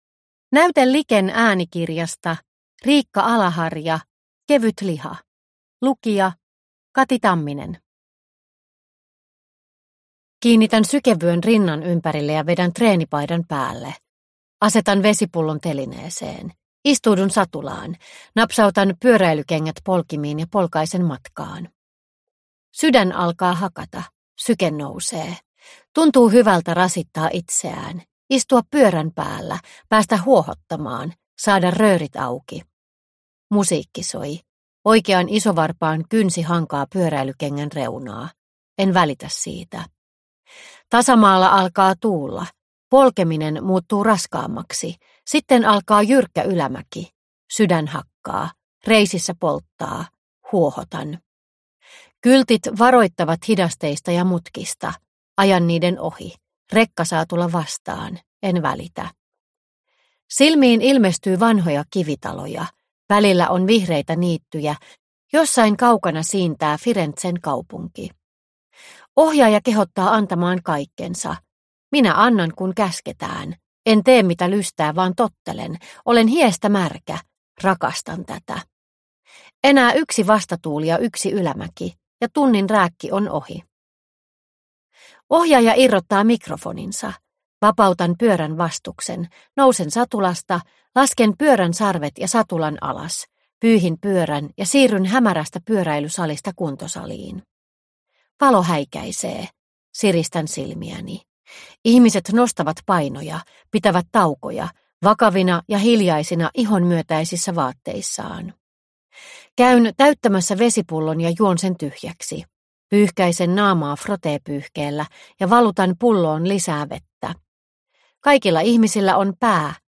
Kevyt liha – Ljudbok – Laddas ner